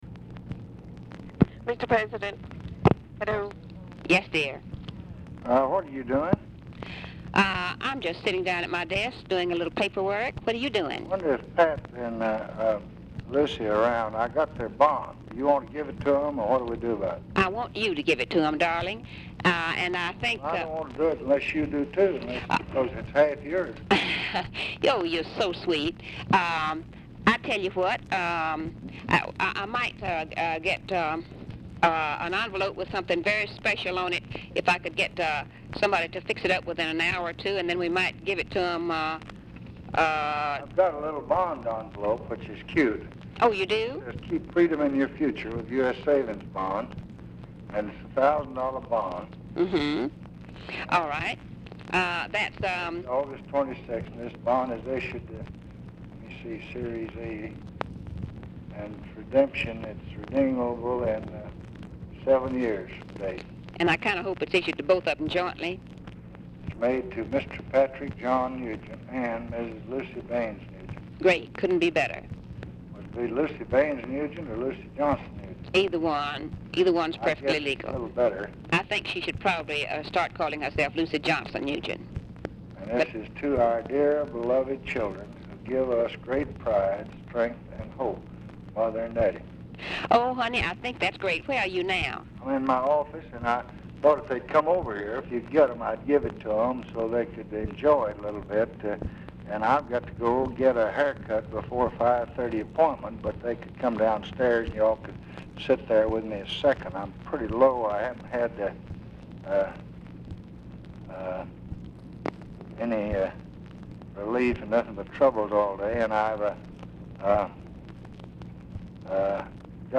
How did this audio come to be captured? Format Dictation belt Location Of Speaker 1 Oval Office or unknown location